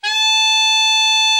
Index of /90_sSampleCDs/Giga Samples Collection/Sax/ALTO SAX